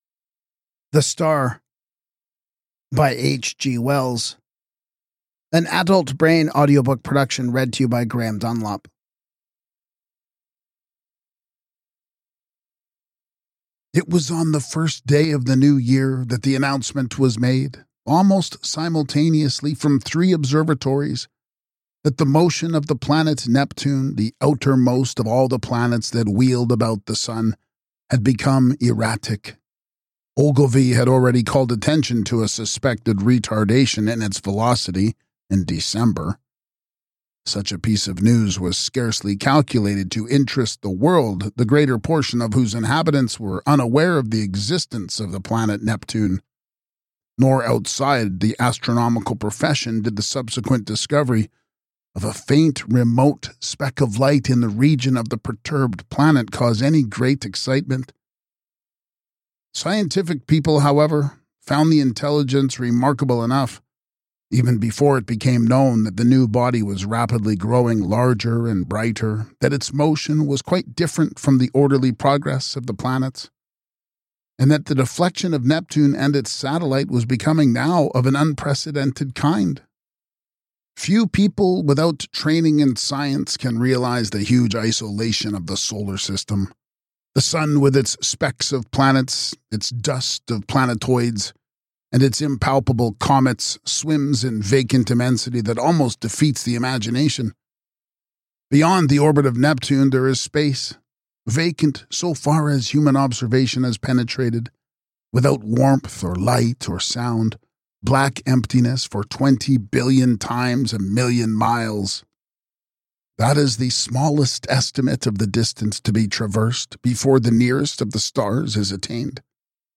Podcast (audiobooks)